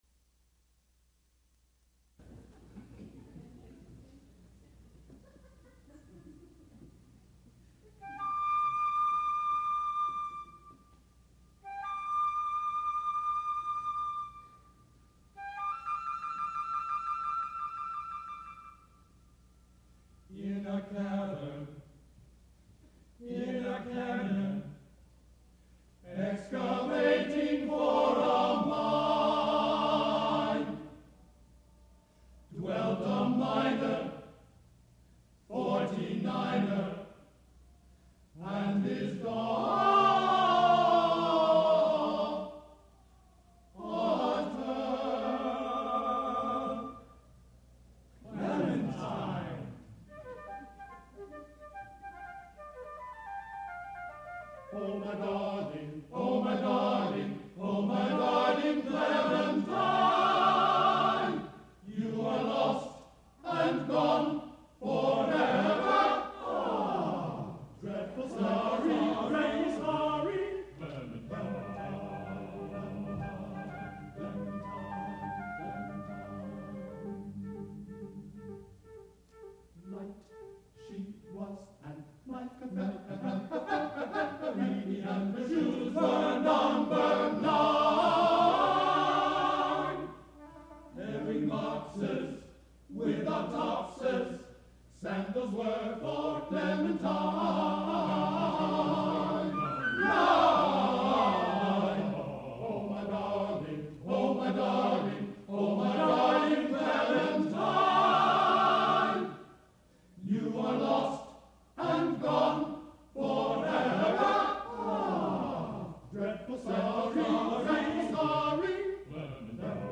for SATB Chorus and Flute (1992)